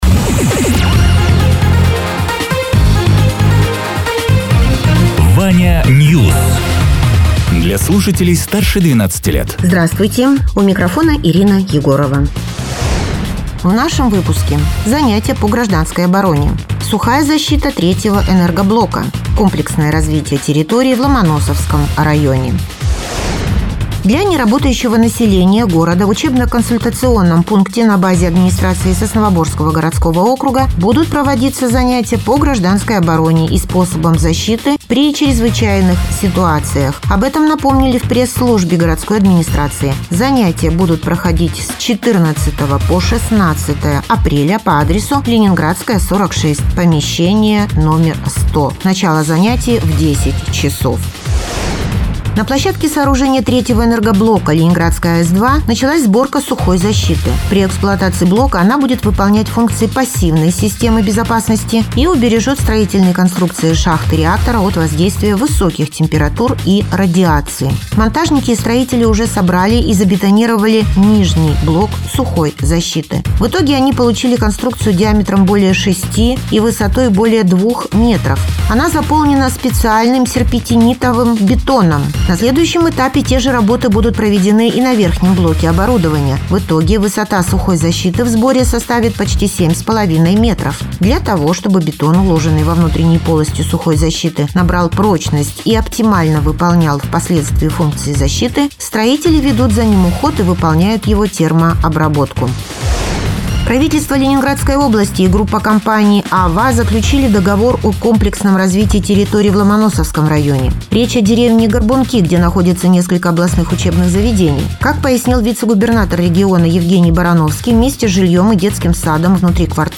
Радио ТЕРА 13.04.2026_10.00_Новости_Соснового_Бора